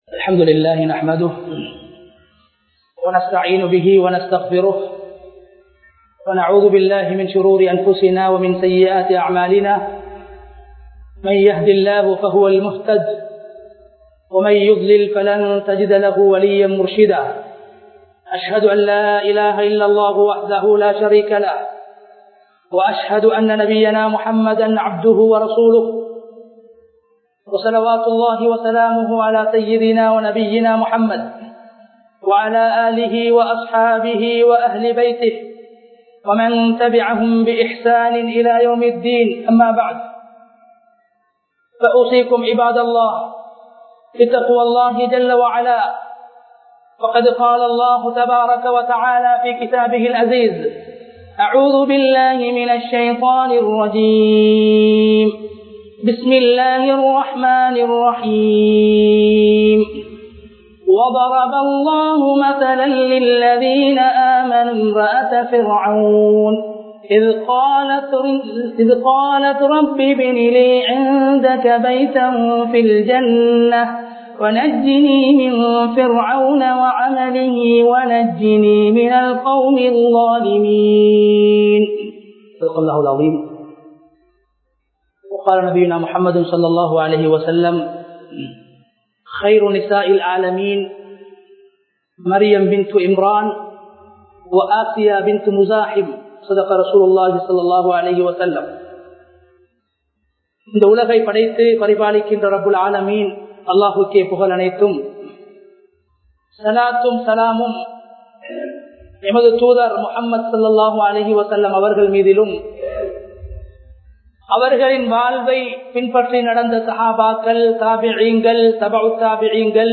பிர்அவ்னுடைய மனைவியின் ஈமான் | Audio Bayans | All Ceylon Muslim Youth Community | Addalaichenai
Samithpura - Mattakuliya Badriya Jumuah Masjith